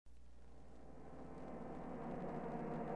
Bwaysimp.mp3 : THX intro with grampa Simpson saying 'Turn it up! Turn it up!" at the end.